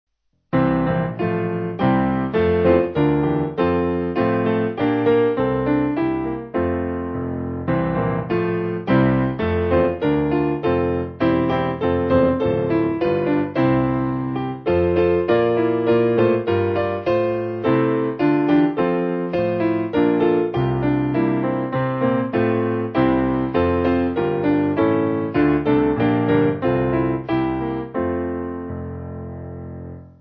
8.7.8.7.D
Simple Piano